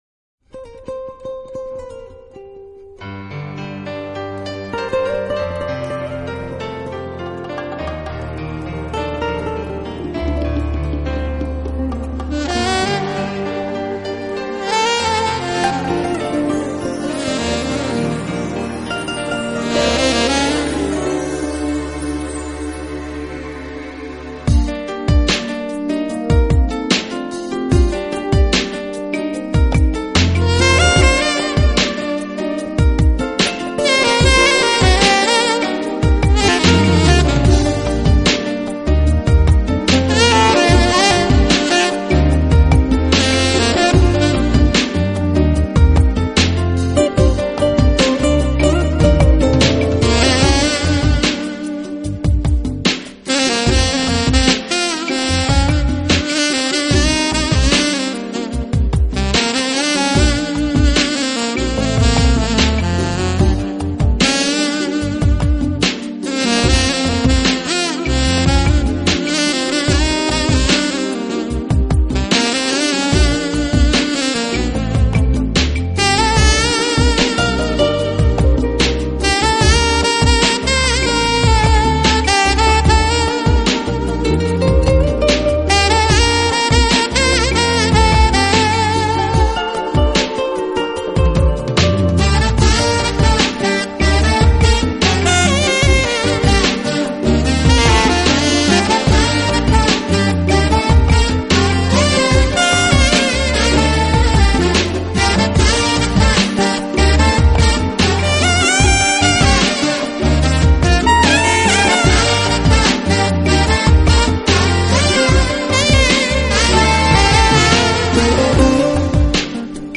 音乐类型: Smooth Jazz
有点拉丁味道的